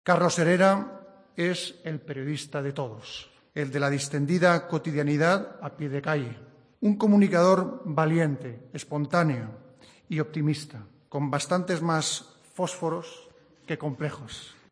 El Rey de España durante la entrega del Premio Mariano de Cavia a Carlos Herrera.